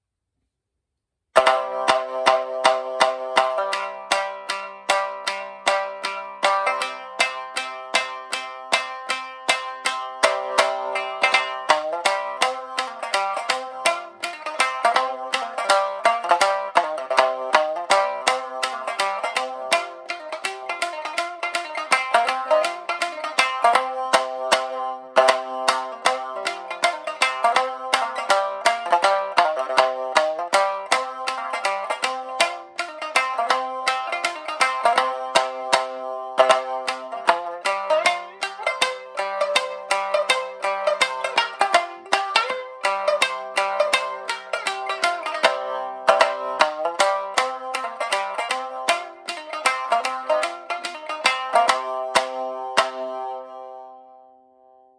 今回は自分の演奏を録音し、ブログの中で聴けるようにしてみたので、時間がありましたら三味線の音も聴きながらブログを読んでいただけると嬉しいです。
ちゃんとした録音機材などを持っているわけではないので、音質はあまりよくないかもしれませんが、ご了承ください。
この旧節は、三味線よりも手踊りを引き立たせるため、テンポが速く、跳ねるような軽快なリズムが特徴的な曲です。